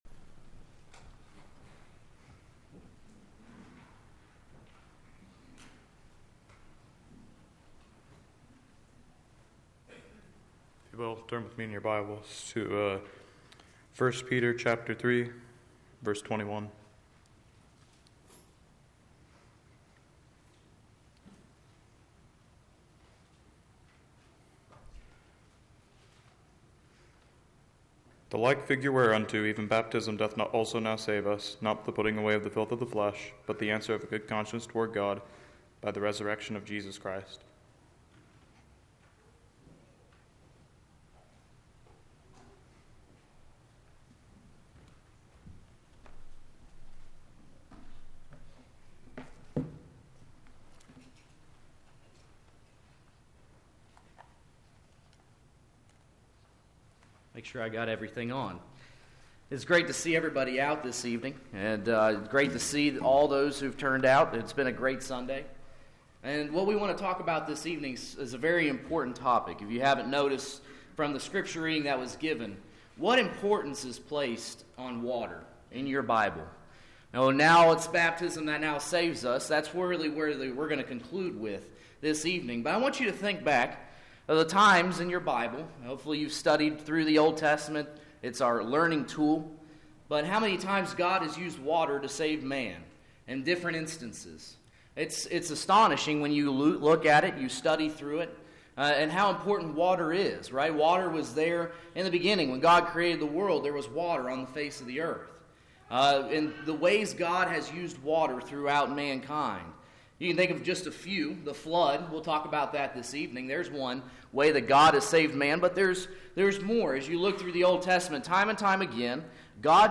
Filed Under: Featured, Lesson Audio